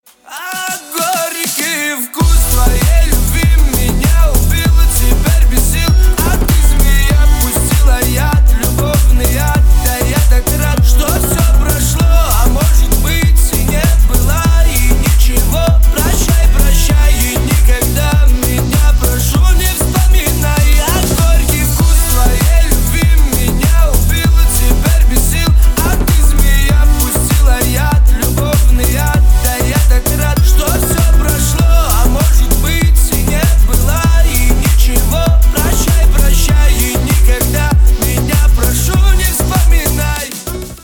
Русский ремикс